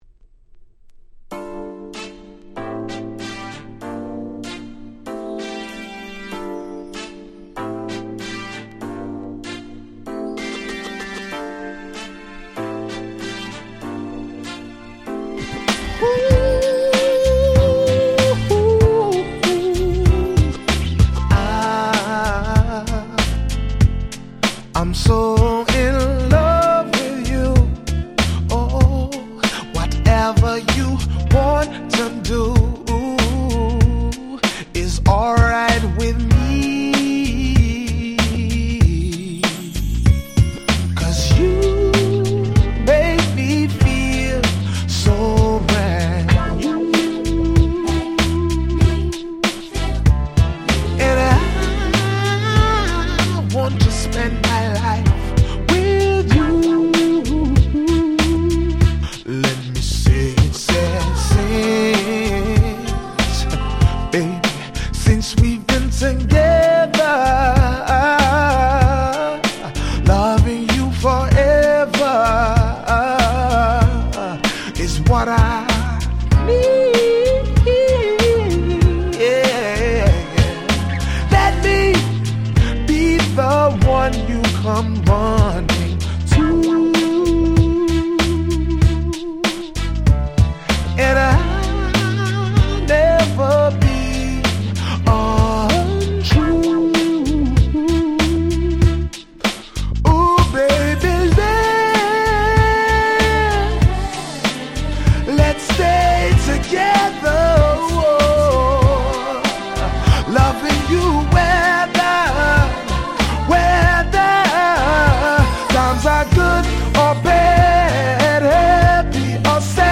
03' Very Nice UK R&B EP !!